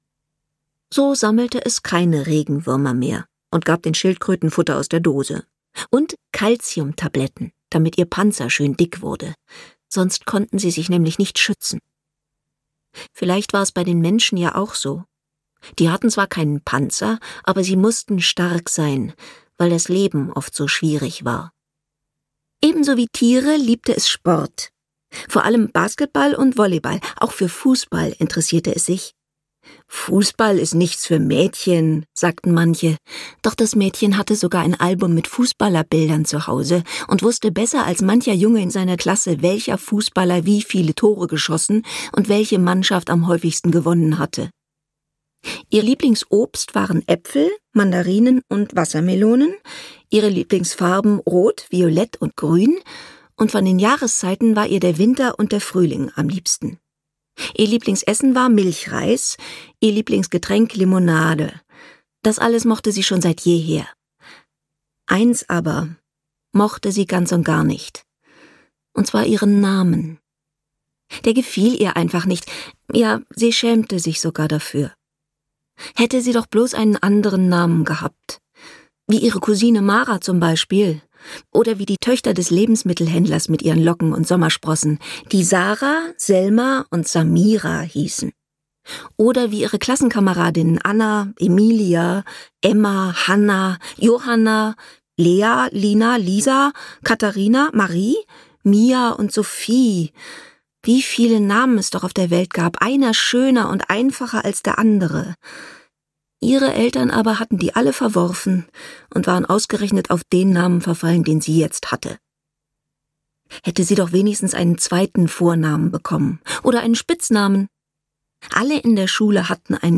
Liane und das Land der Geschichten - Elif Shafak - Hörbuch